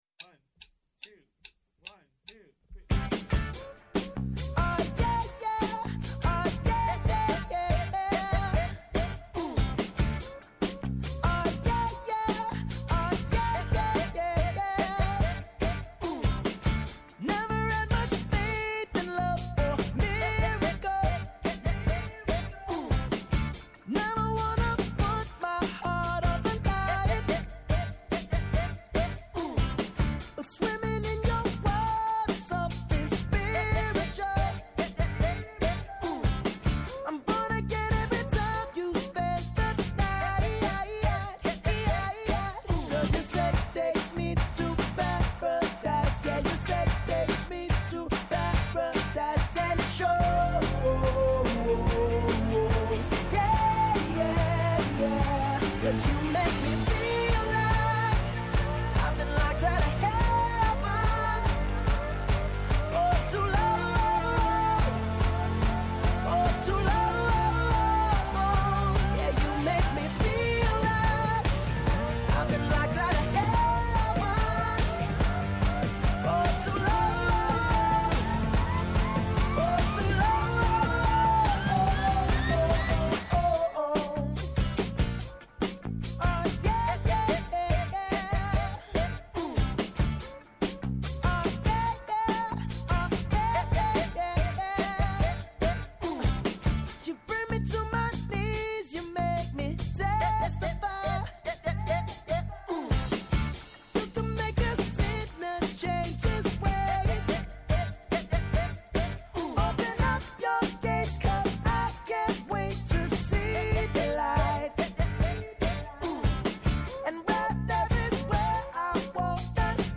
-Ο Χάρης Θεοχάρης, Υφυπουργός Οικονομικών
Συνεντεύξεις